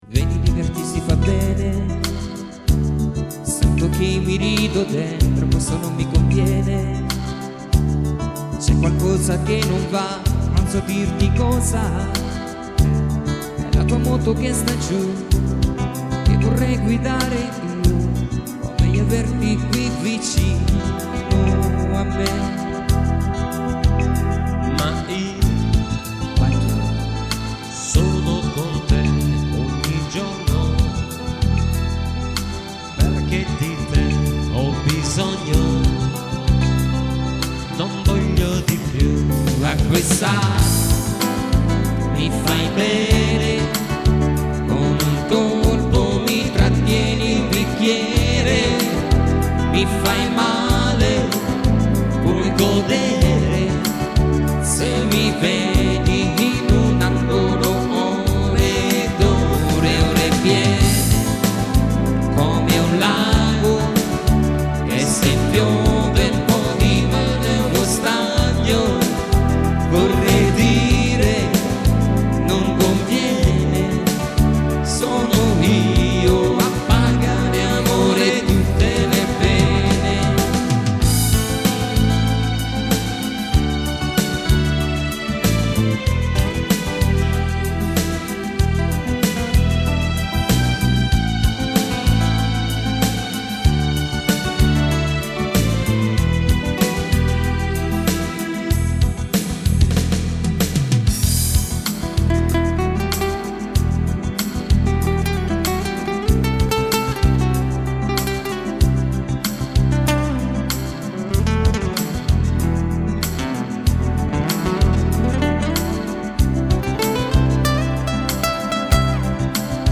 chitarra
tastiera e fisarmonica